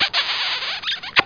SFX吻3(KISS3)音效下载
SFX音效